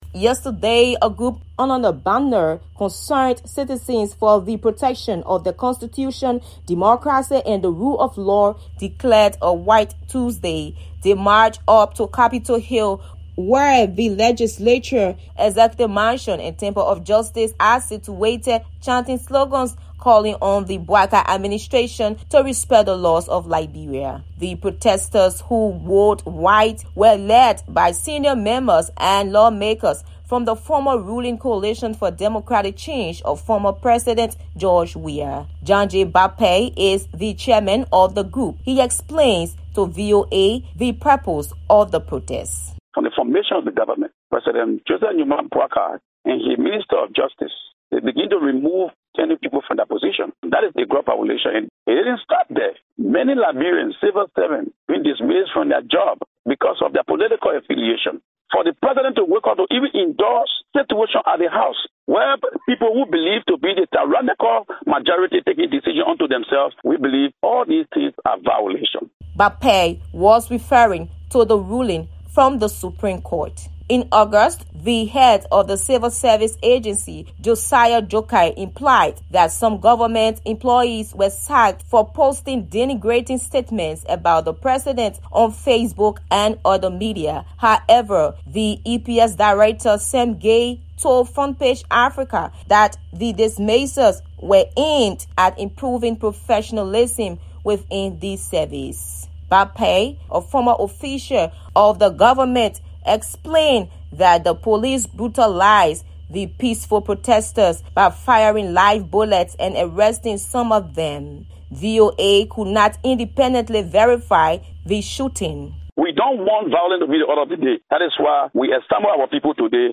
reports from Monrovia